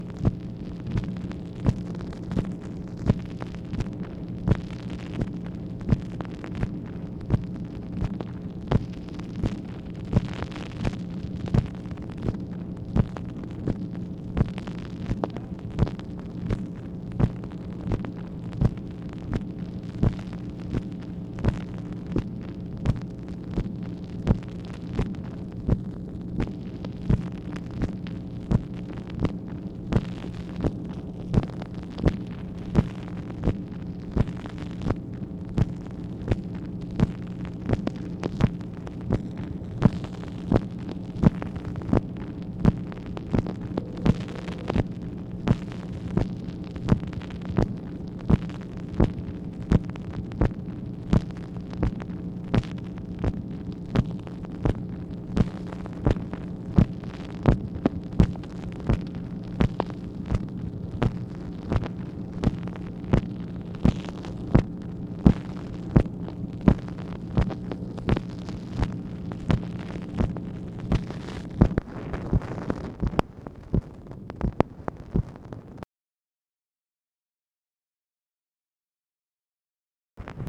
MACHINE NOISE, August 4, 1964
Secret White House Tapes | Lyndon B. Johnson Presidency